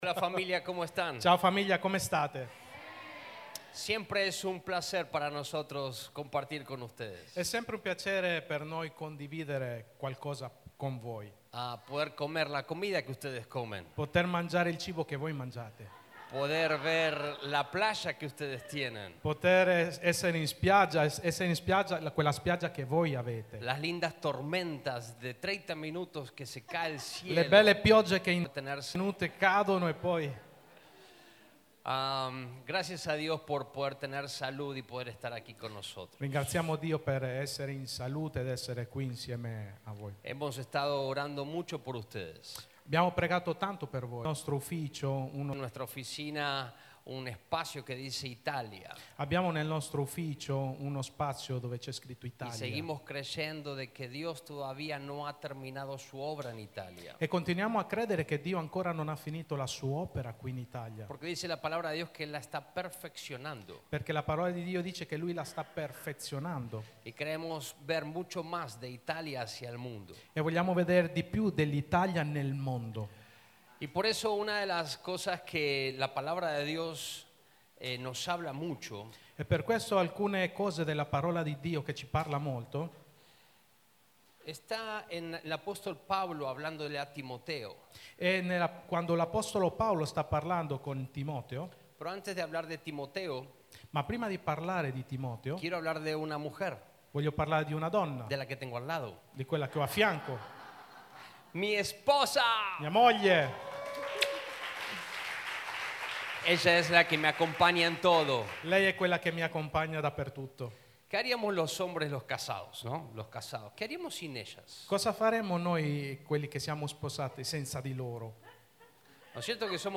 18/06/2025 – Worship Service RdM